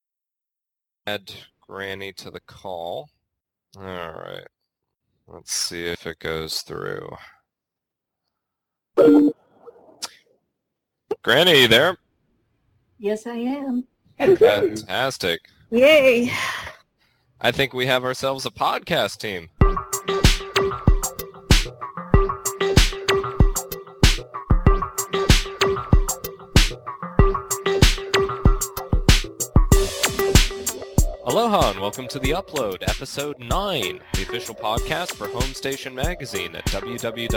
A: American sarcasm, British sarcasm, Franco-Irish sarcasm and cantankerous sarcasm.